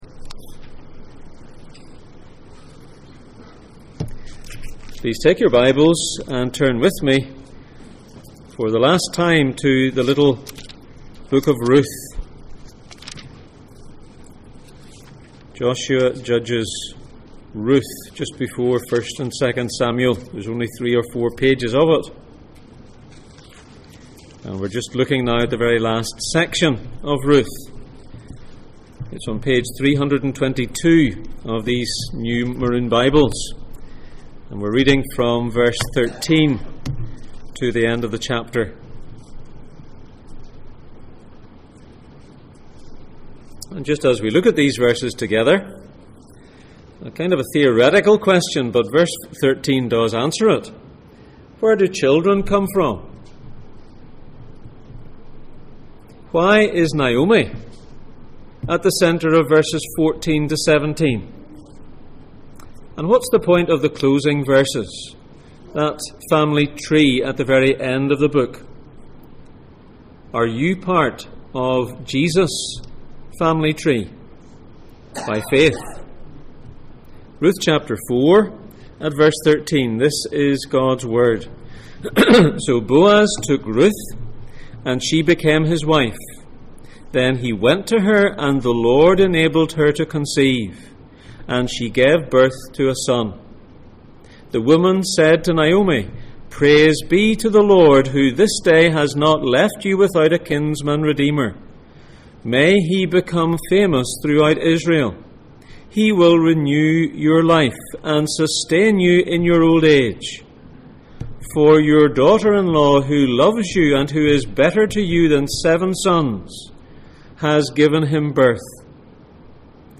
Ruth Passage: Ruth 4:13-22, 1 Samuel 17:48-50, 2 Chronicles 22:10-12, Romans 8:28 Service Type: Sunday Morning